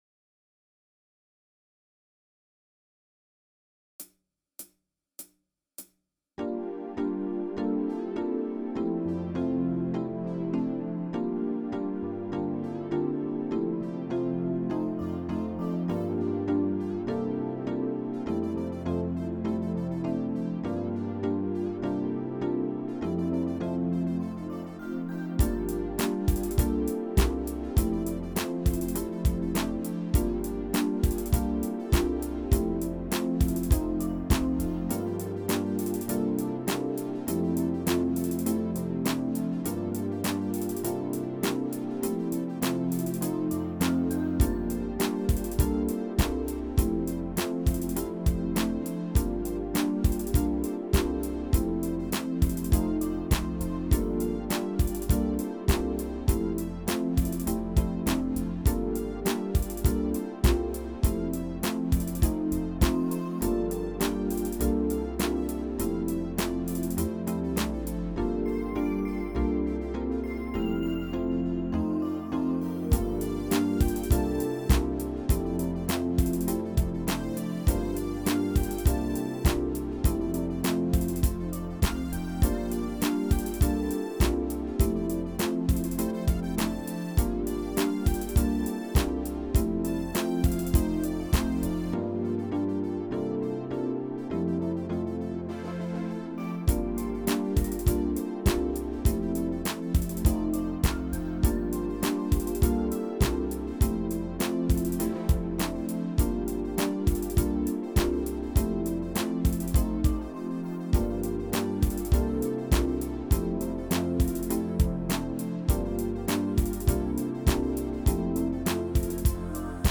versión instrumental multipista